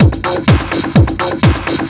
DS 126-BPM B5.wav